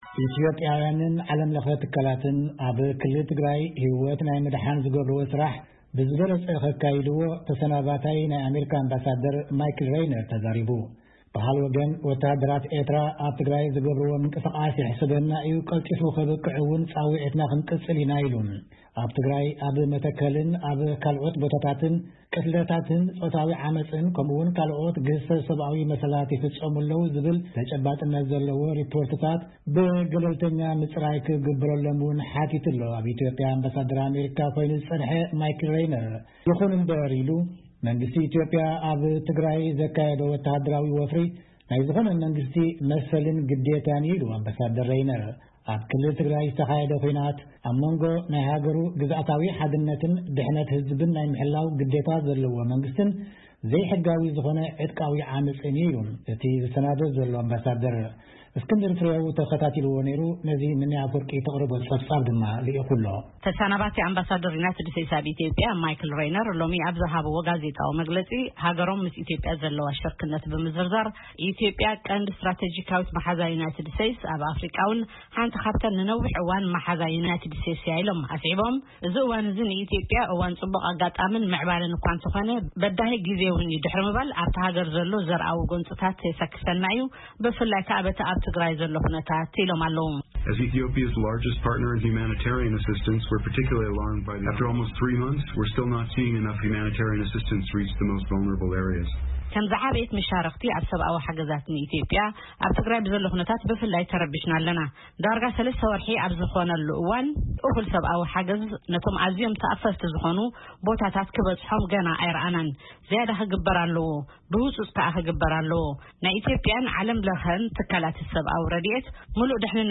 መግለጺ ተሰናባቲ ኣምባሳደር ኣሜሪካ ኣብ ኢትዮጵያ ማይክል ሬይነር